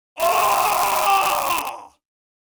Screams Male 03
Screams Male 03.wav